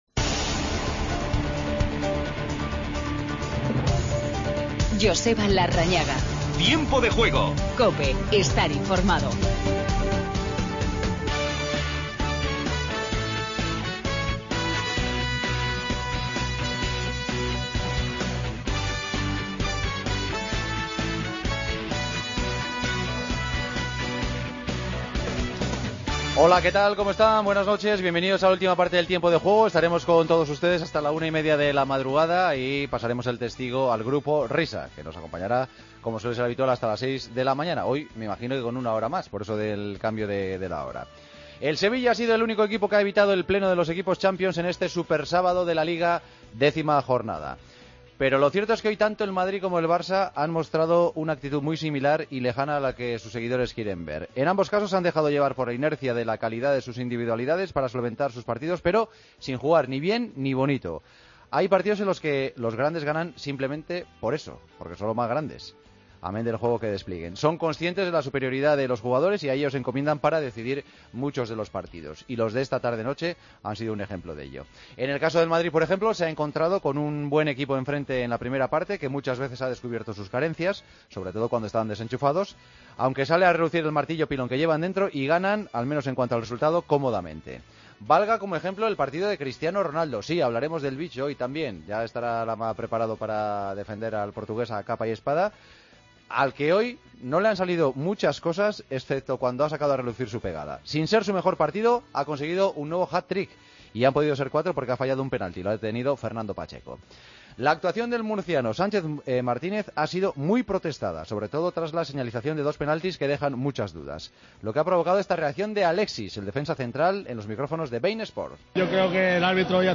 Escuchamos a Sergi Roberto y a Keylor Navas. Entrevistas a Theo Hernández y a Carrasco.